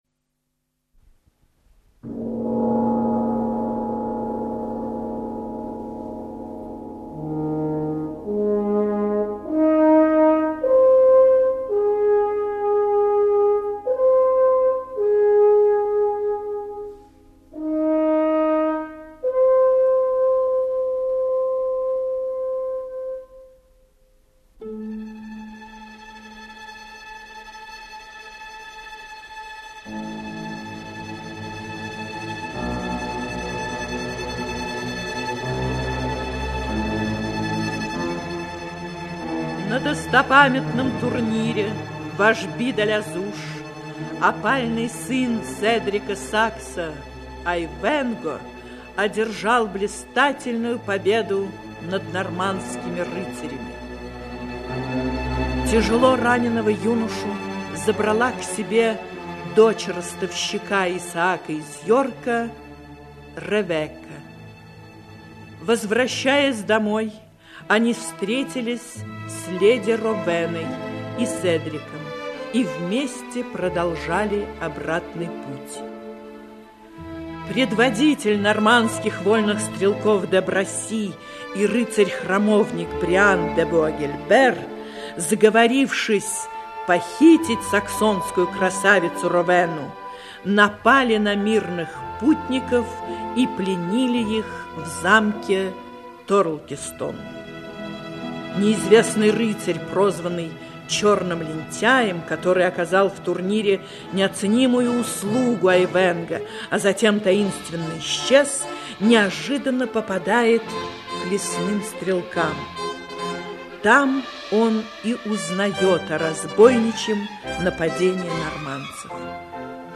Аудиокнига Айвенго (спектакль) Часть 3-я. Ричард – Львиное Сердце | Библиотека аудиокниг
Aудиокнига Айвенго (спектакль) Часть 3-я. Ричард – Львиное Сердце Автор Вальтер Скотт Читает аудиокнигу Актерский коллектив.